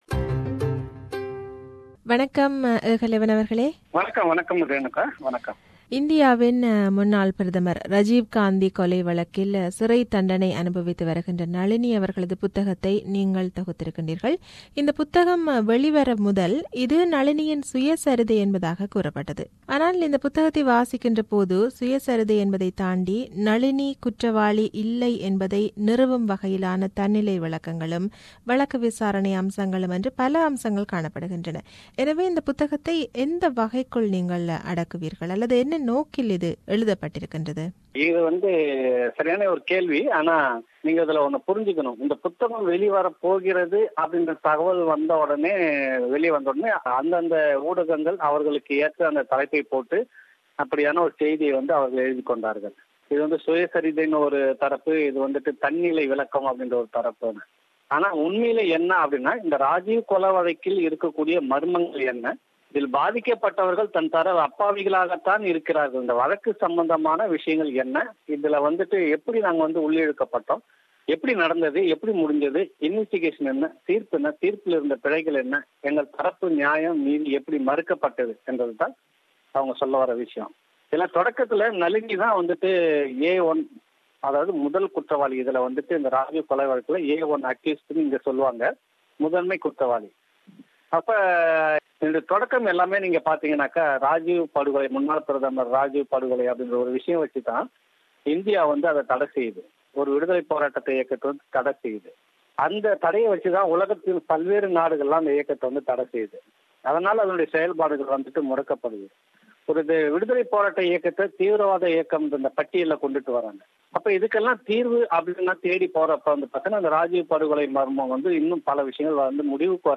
This is an interview with him.